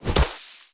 Sfx_creature_pinnacarid_hop_slow_01.ogg